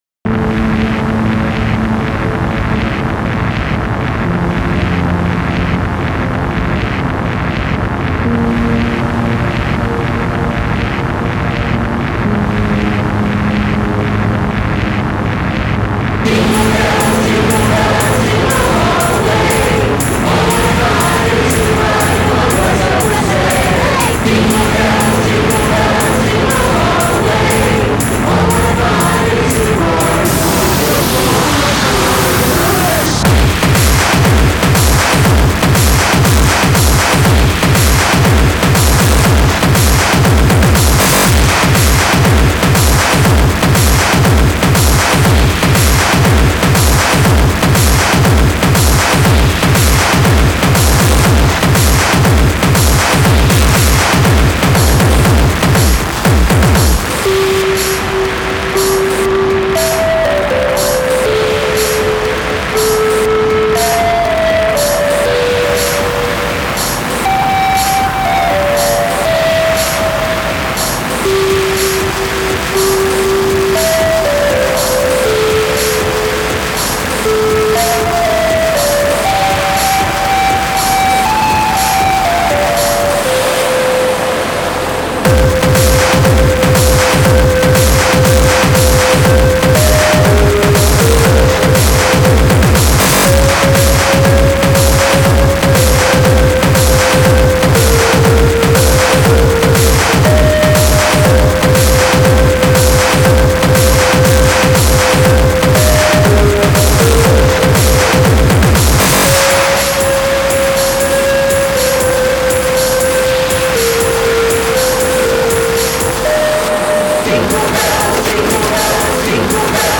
Industrial, Rhythmic Noise, xmas, Christmas